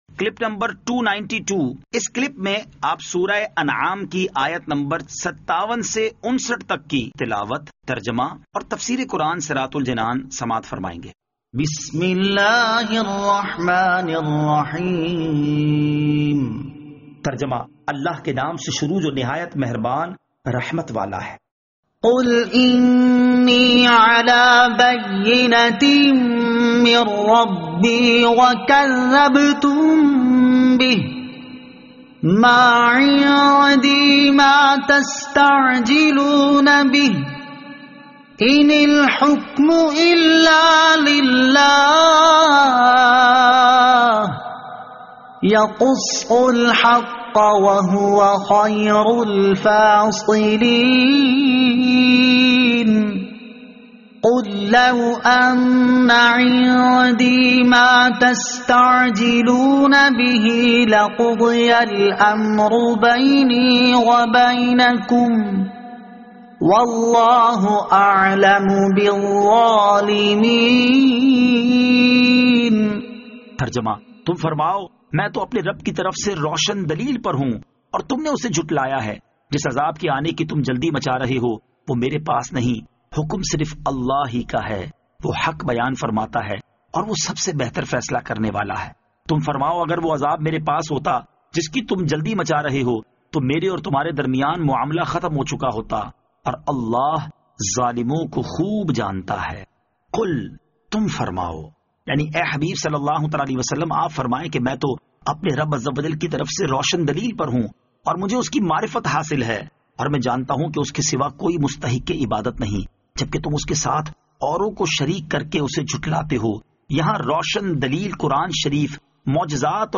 Surah Al-Anaam Ayat 57 To 59 Tilawat , Tarjama , Tafseer